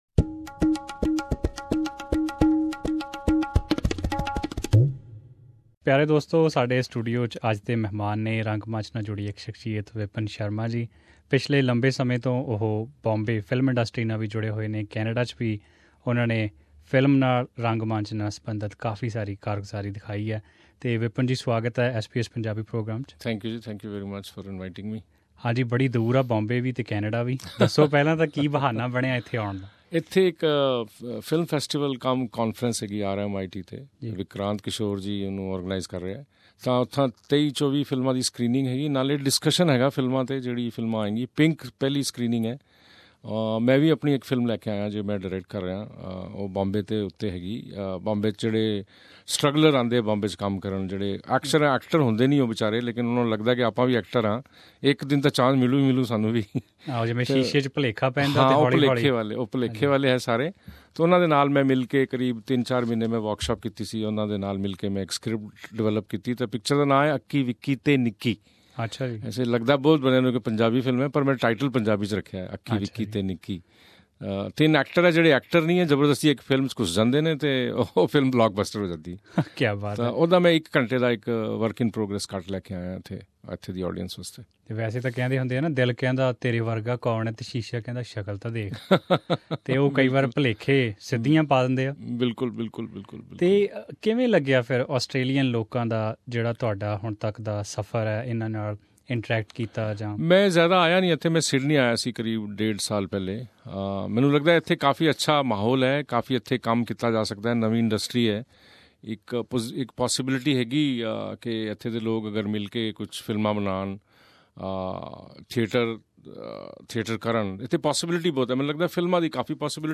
Vipin Sharma at SBS Melbourne Studio Source